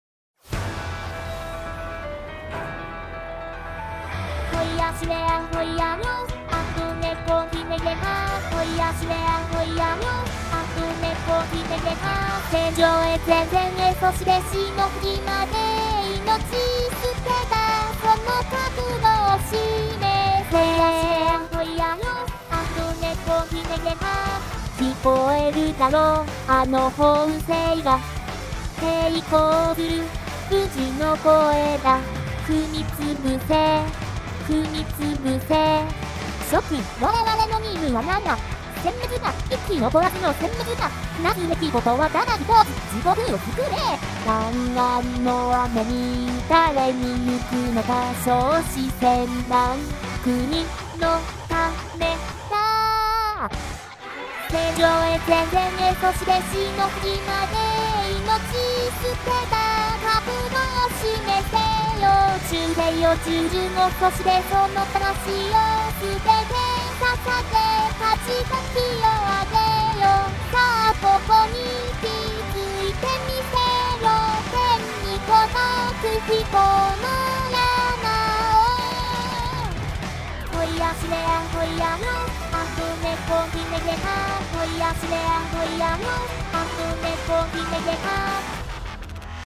好久不见顺手做了个VSQx，霓虹人真是德棍之心不死啊又搞（发音微妙的）德语
很闲【？】所以就连独白都做了，听个乐呵就行
依旧是不会混响的试听→